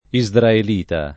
isdraelita [ i @ drael & ta ]